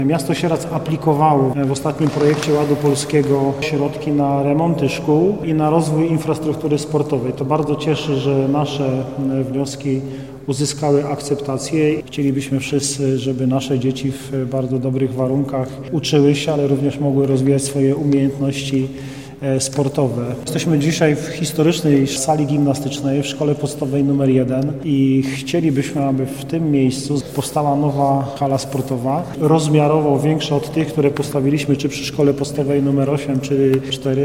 Rozbudowa infrastruktury sportowej w Sieradzu W planach jest przebudowa sali gimnastycznej na terenie Miejskiego Ośrodka Sporu i Rekreacji, a także remont kortów tenisowych z zadaszeniem, przebudowa stadionu w centrum miasta na osiedlu Jaworowym, a także remont i rozbudowa sali sportowej przy Szkole Podstawowej nr 1, wylicza prezydent Sieradza, Paweł Osiewała.